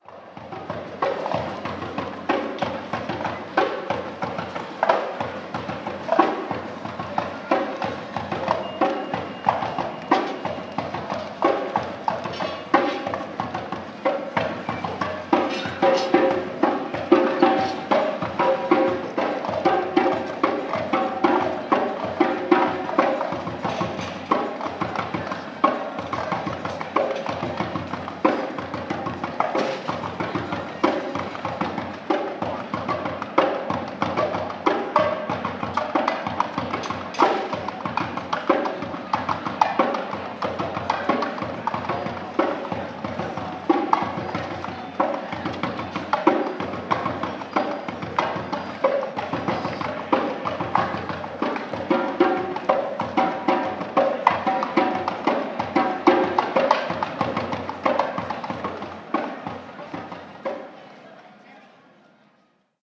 Ambiente de calle con música
timbal
percusión
murmullo
música instrumental
ritmo
Sonidos: Gente
Sonidos: Ciudad